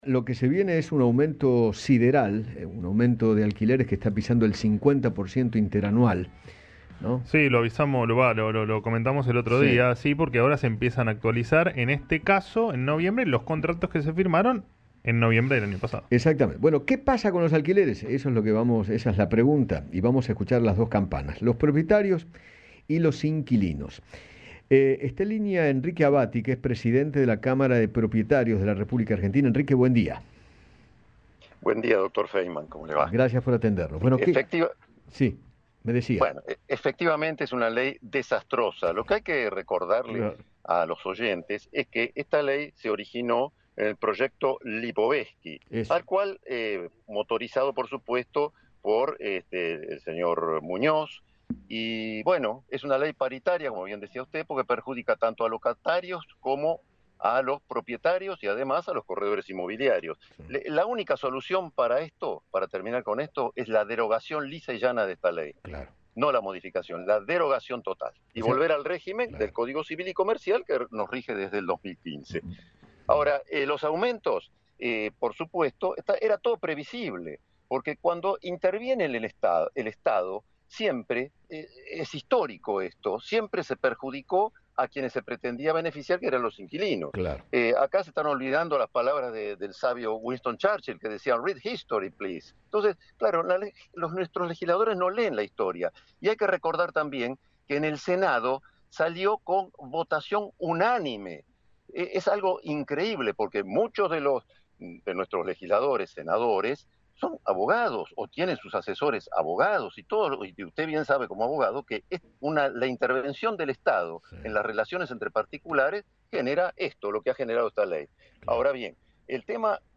habló con Eduardo Feinmann sobre el precio de los alquileres y criticó duramente a dicha ley.